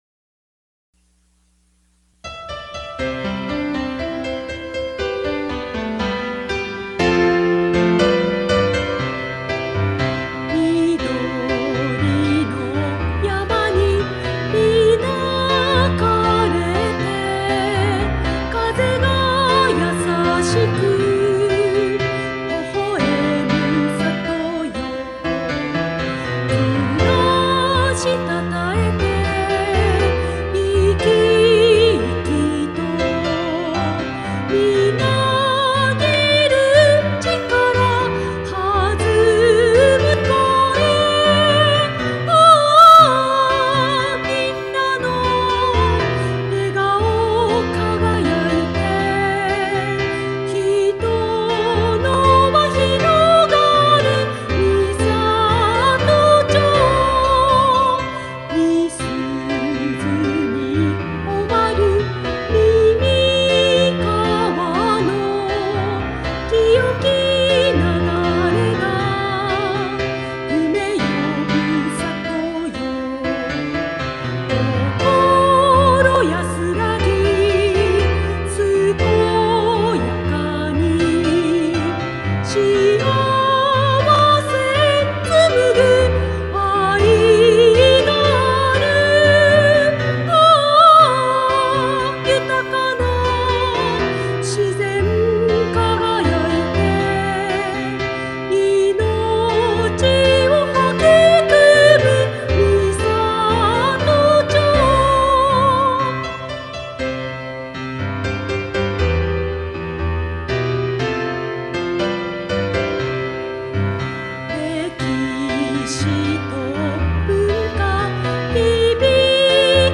・曲調　　厳かな場や町旗掲揚時の斉唱等に適した曲調
女性独唱　フルコーラス　音楽データ（ファイル：3.25メガバイト） 別ウィンドウで開きます